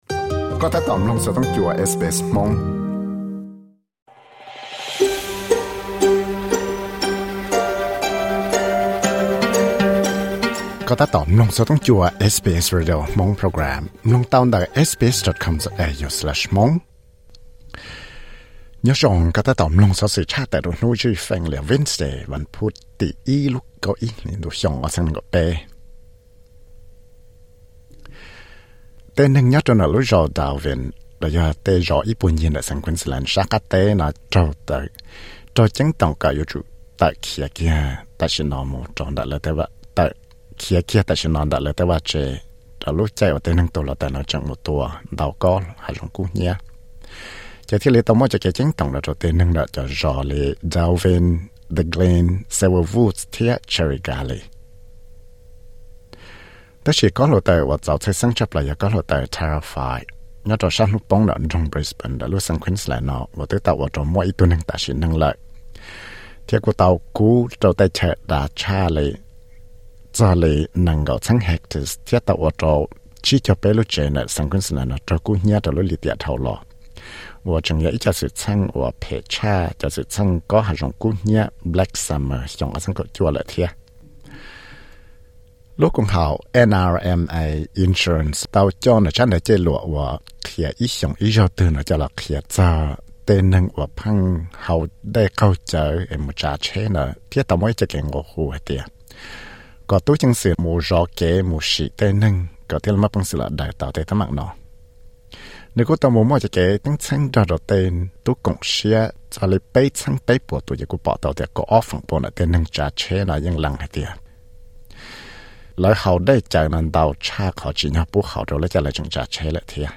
Xov xwm luv tshaj tawm hnub zwj Feej (Wednesday newsflash 01.11.2023).